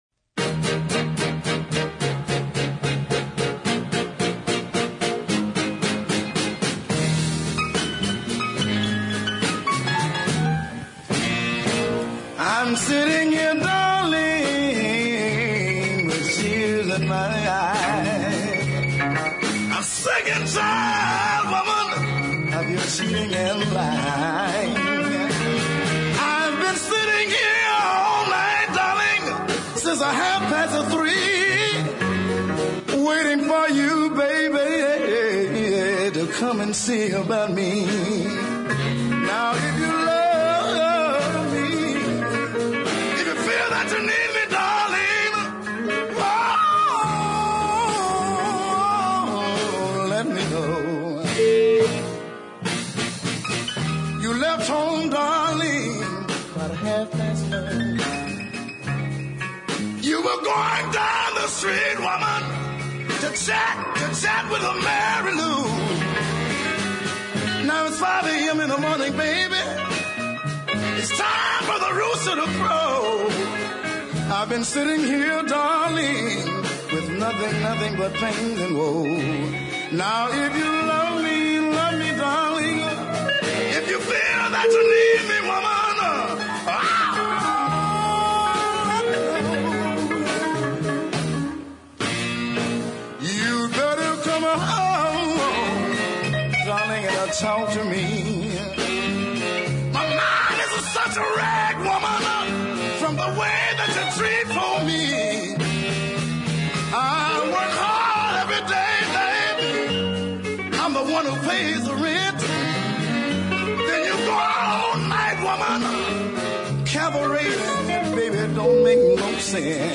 another fierce and compelling vocal
over a blues ballad arrangement
Great guitar and horn support too.